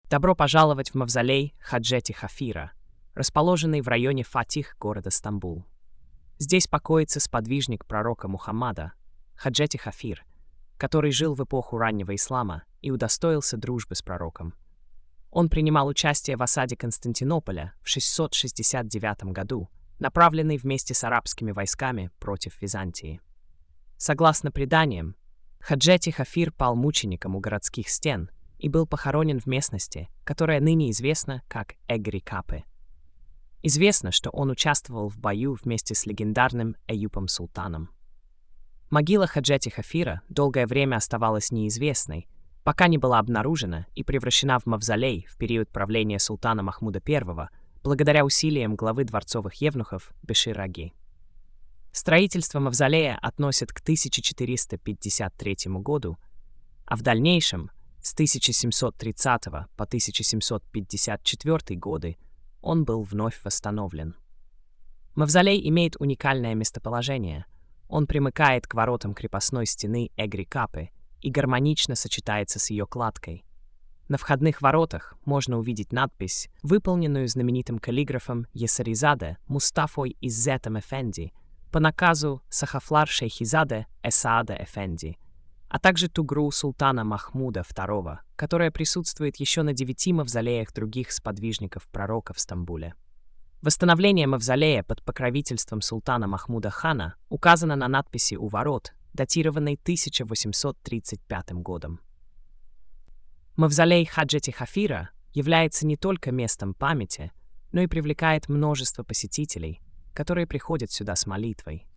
Аудиоповествование: